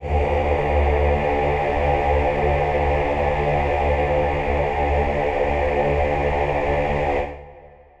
Choir Piano
D2.wav